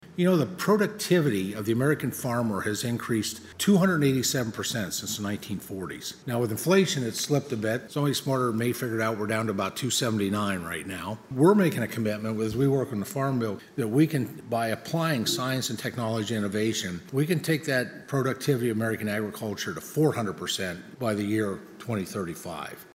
A panel discussion, hosted by Farm Journal Foundation and Kansas State University Tuesday at the Stanley Stout Center, focused on how agricultural innovations can mitigate global hunger and malnutrition.